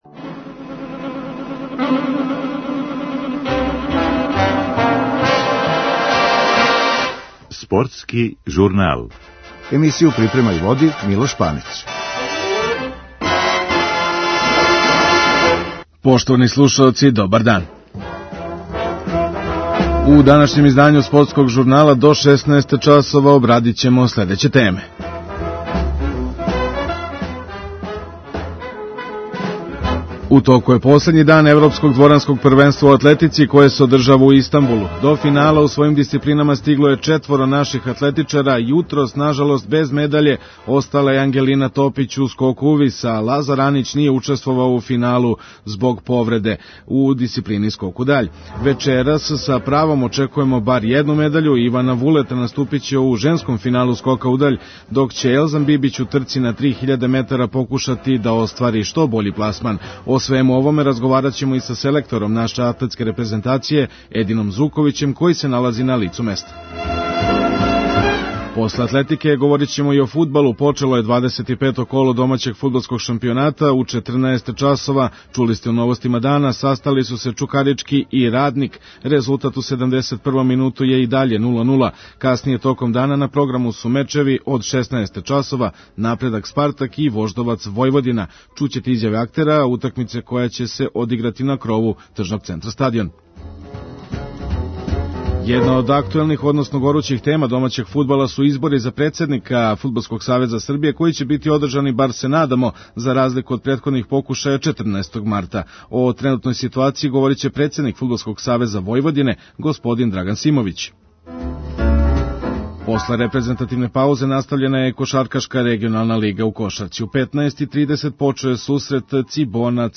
Чућете изјаве актера утакмице која ће се одиграти на крову тржног центра Стадион.